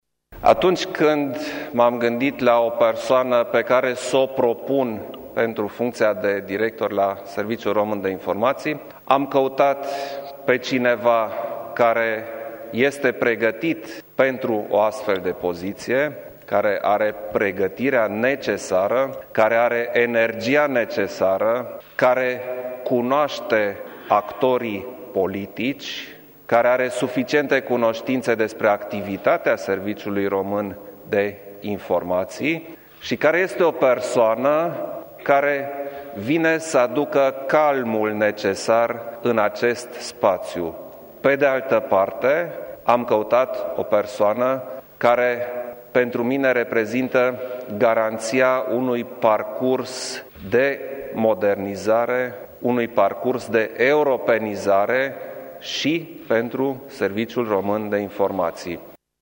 Cu puţin timp în urmă, președintele Klaus Iohannis a făcut acest anunţ, la Palatul Cotroceni, precizând că a făcut această nominalizare după ce a discutat, în cursul zilei, cu liderii partidelor parlamentare și cu cei doi președinți ai Parlamentului.
Președintele Iohannis a explicat ce îl recomandă pe Eduard Hellvig pentru această poziție: